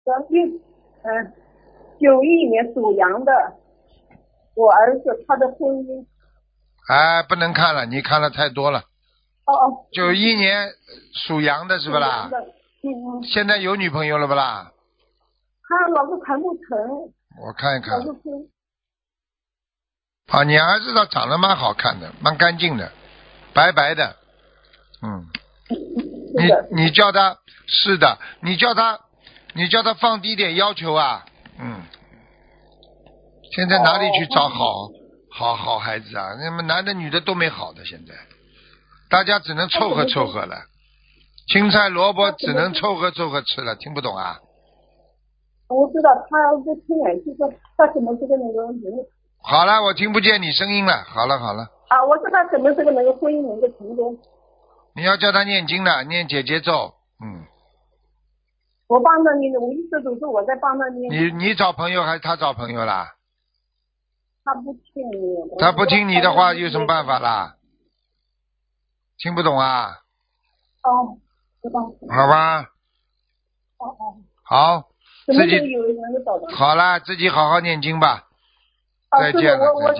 目录：☞ 2019年04月_剪辑电台节目录音_集锦